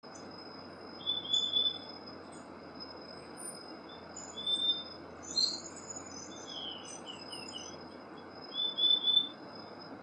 Tangará Común (Euphonia chlorotica)
Fase de la vida: Adulto
Localidad o área protegida: Parque Nacional Calilegua
Condición: Silvestre
Certeza: Vocalización Grabada
Tangara-Comun-Jujuy-D4-072.mp3